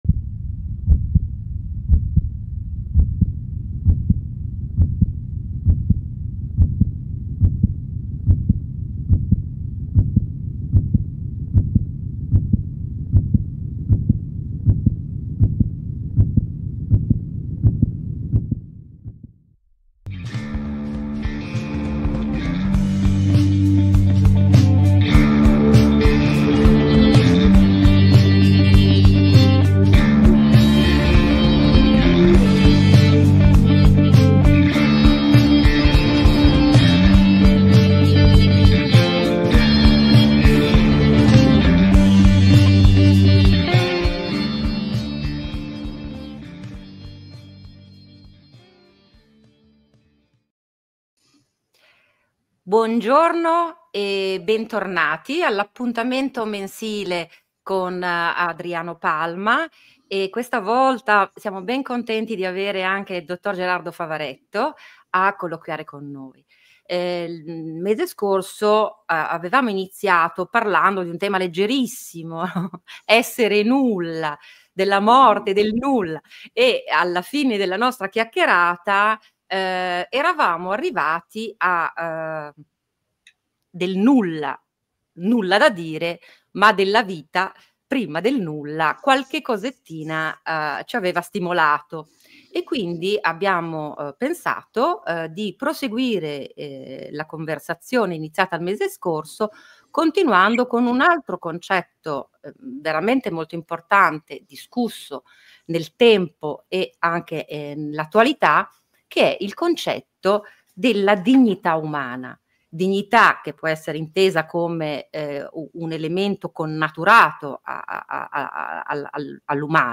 In onda in diretta la prima Domenica di ogni mese.